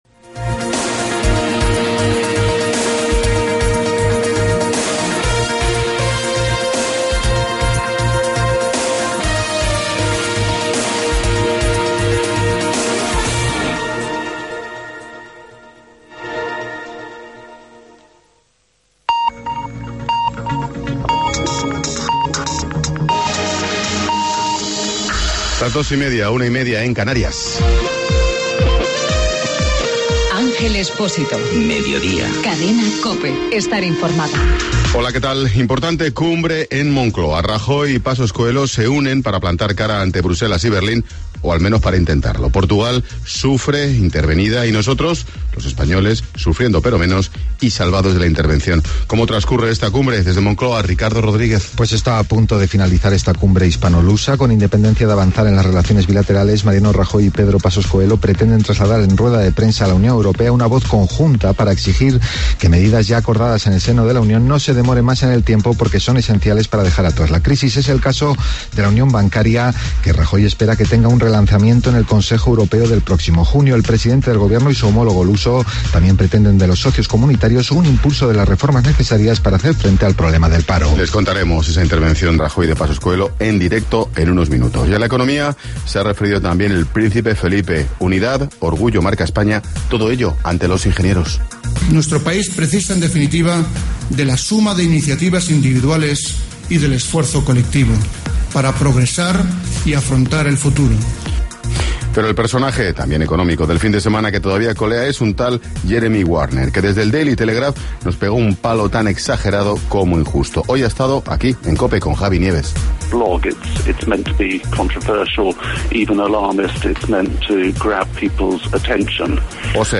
Informativo mediodía, lunes 13 de mayo